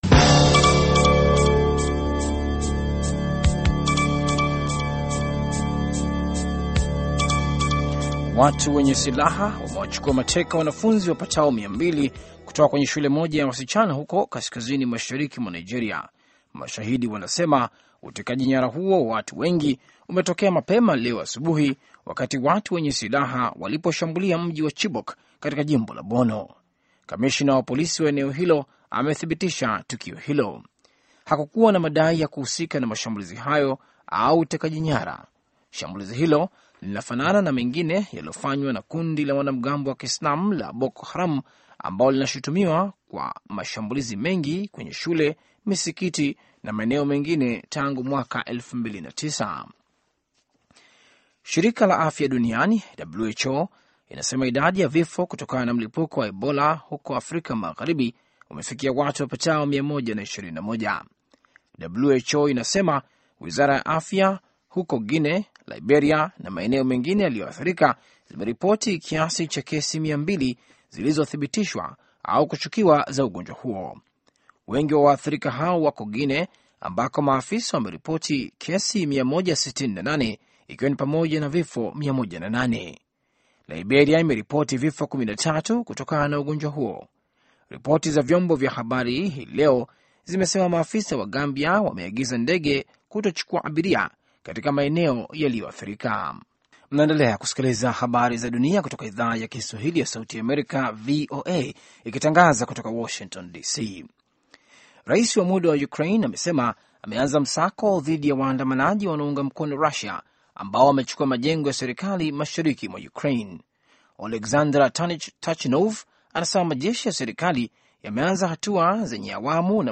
Taarifa ya Habari VOA Swahili - 6:13